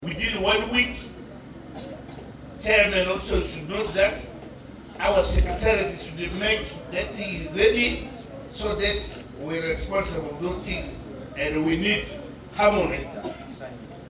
Buga Matata the Resident District commissioner of Maracha District .mp3